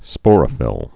(spôrə-fĭl)